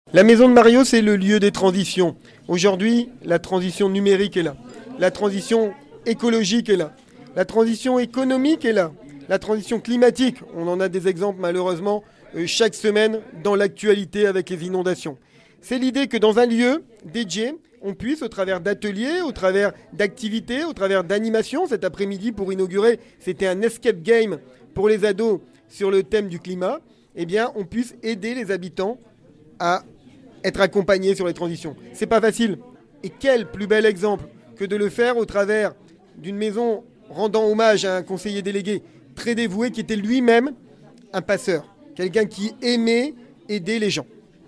David Bailleul, le maire de Coudekerque-Branche :